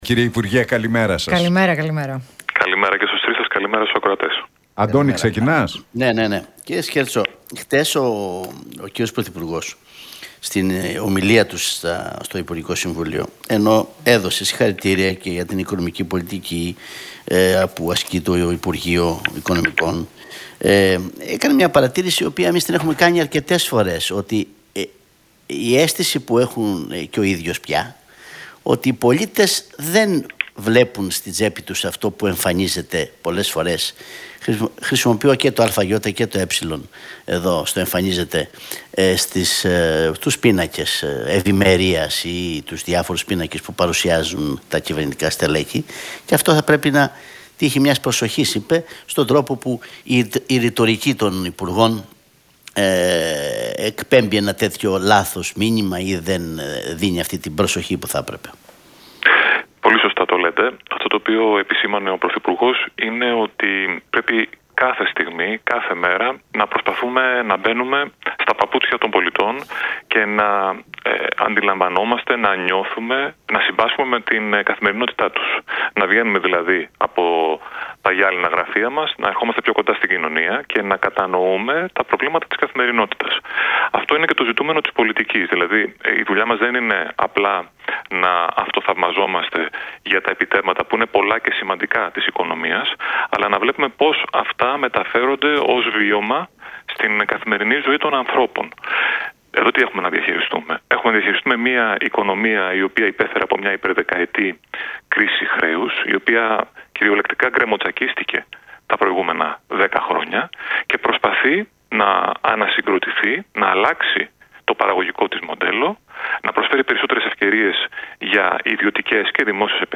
Από τα μηνύματα που έστειλε ο πρωθυπουργός, Κυριάκος Μητσοτάκης, στο Υπουργικό Συμβούλιο ξεκίνησε η συνέντευξη του υπουργού Επικρατείας Άκη Σκέρτσου στον Realfm 97,8 και στους Νίκο Χατζηνικολάου